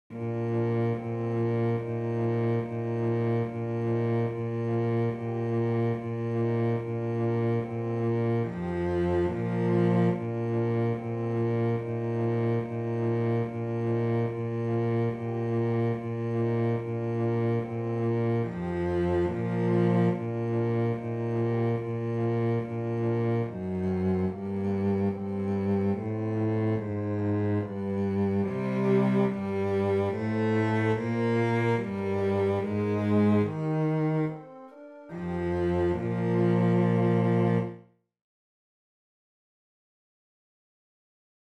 Chorproben MIDI-Files 508 midi files